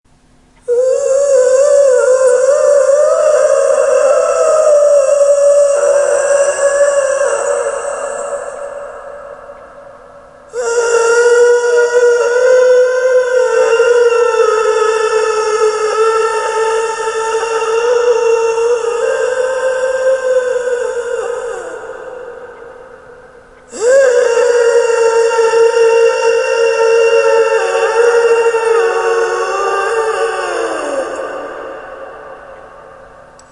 Download Moaning sound effect for free.
Moaning